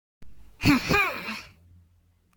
Bounce
2d 3d adventure arcade audio boing bounce cartoon sound effect free sound royalty free Movies & TV